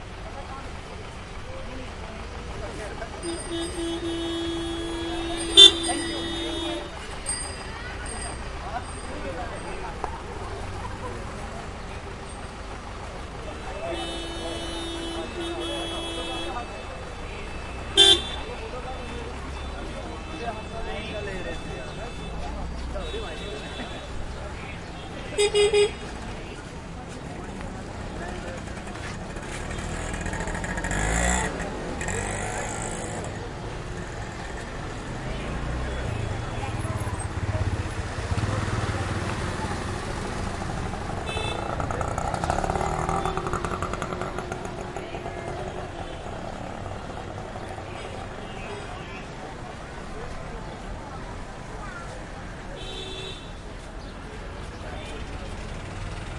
加沙 " 交通媒介 中东紧张的十字路口市场入口处有袅袅的摩托车口哨声和警察的喇叭声2更多
描述：交通媒介中东紧密交叉口市场入口与嘶哑的摩托车口哨警察和喇叭鸣喇叭2更多喇叭和车轮加沙2016.wav
Tag: 交叉口 交通 中东 城市